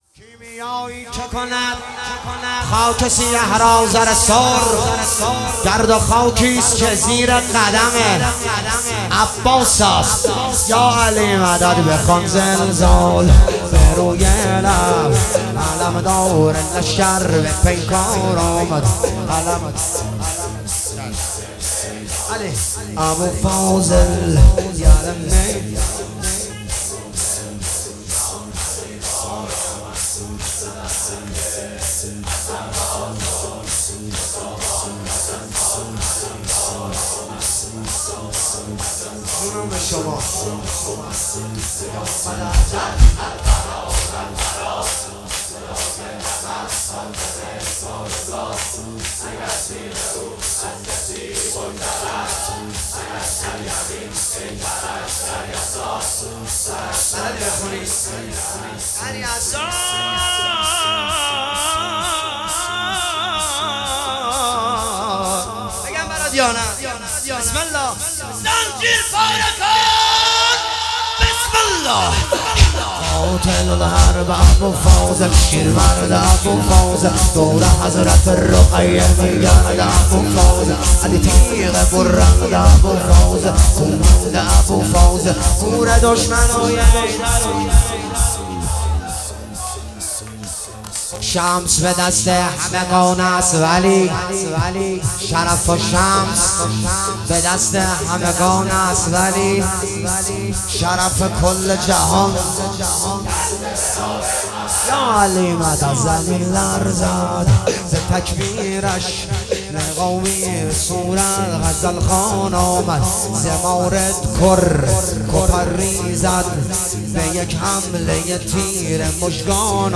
جلسه هفتگی اردیبهشت 1404